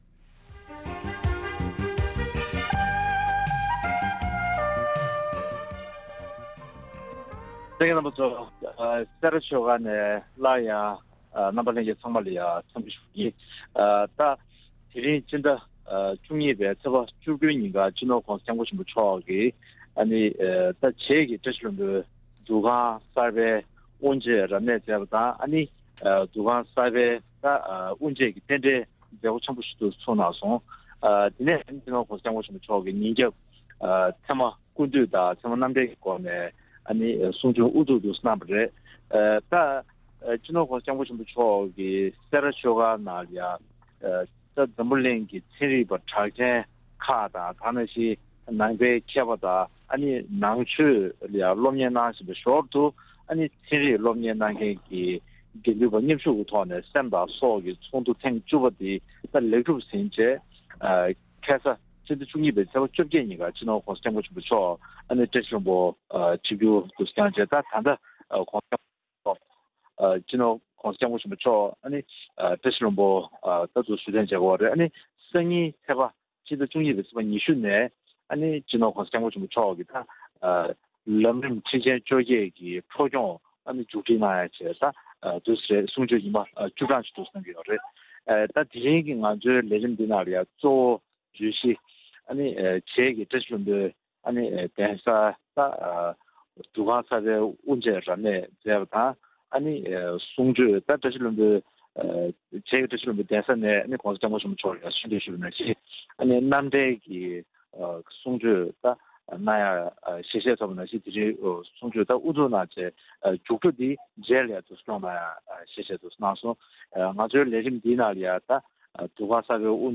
༄༅། །ཐེངས་འདིའི་གནད་དོན་གླེང་མོལ་གྱི་ལེ་ཚན་ནང་དུ།